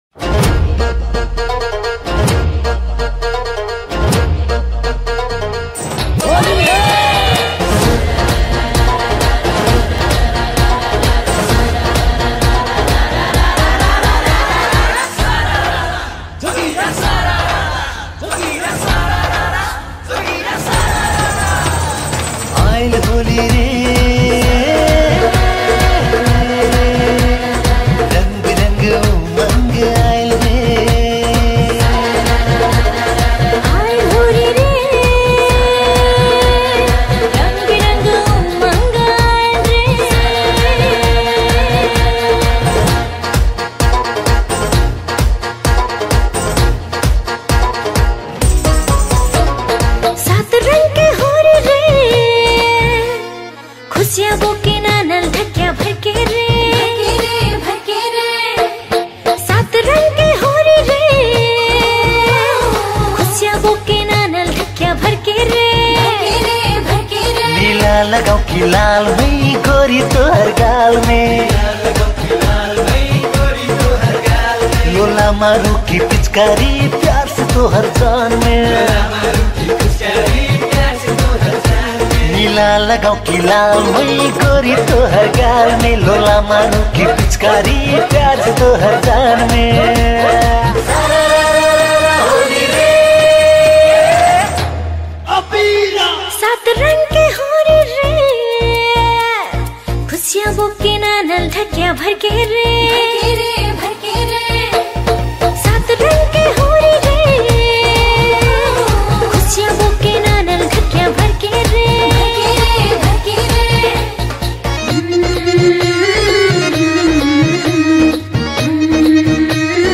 Tharu Holi Song